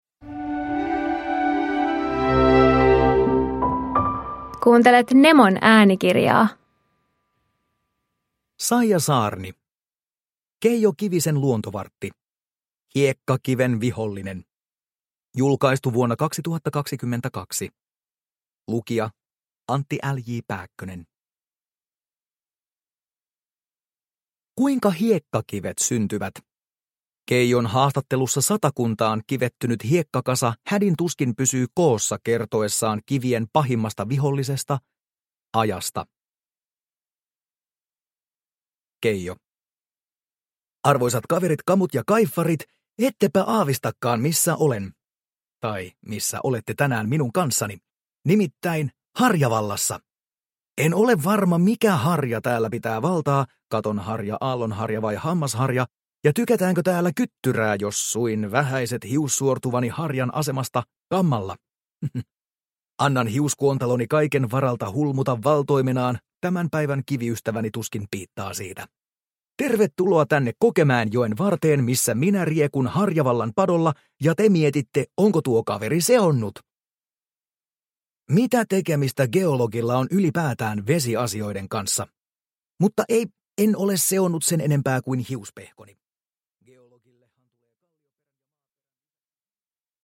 Hiekkakiven vihollinen – Ljudbok – Laddas ner